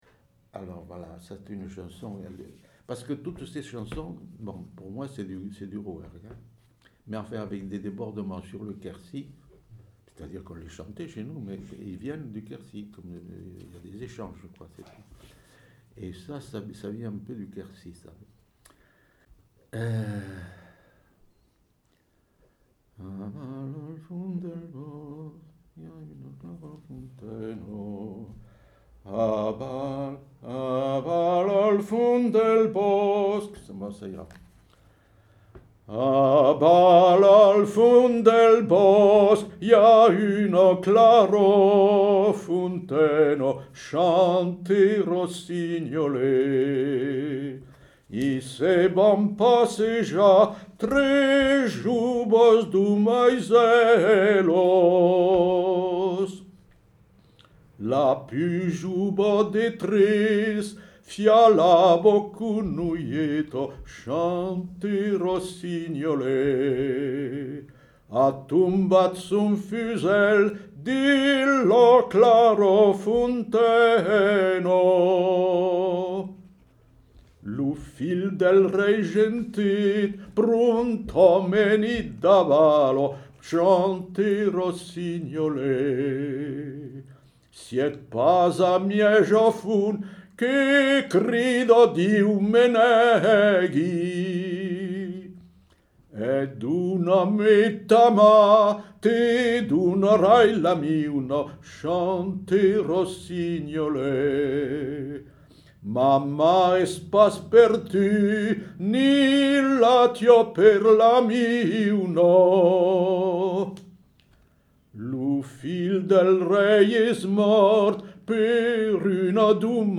Aire culturelle : Rouergue
Lieu : Saint-Sauveur
Genre : chant
Effectif : 1
Type de voix : voix d'homme
Production du son : chanté
Ecouter-voir : archives sonores en ligne